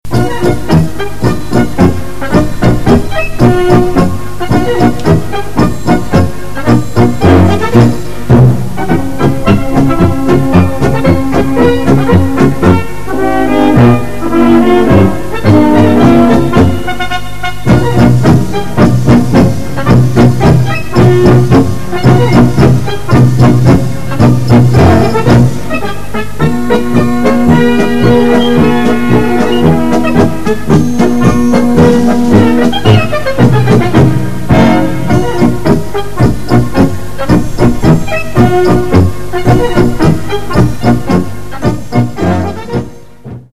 Gattung: Quadrille
Besetzung: Blasorchester